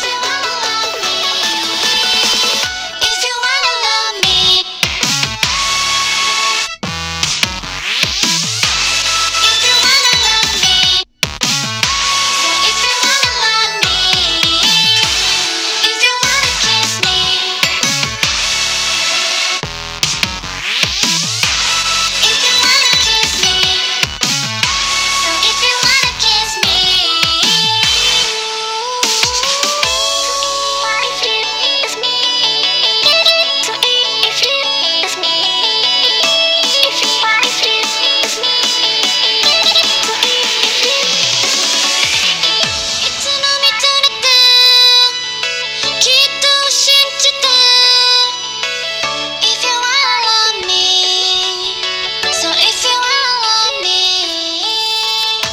0分50秒あたりから1分50秒くらいまでの約1分間を録音して比較しました。
録音機材：OLYMPUS LINEAR PCM RECORDER LS-20M
録音設定：PCM 44.1KHz/16bit（CDと同じ）無圧縮WAV出力 > 128kbps MP3
レコーダーのマイクは比較的高音域の感度が高いので、一般的にはキンキンした音になりがちです。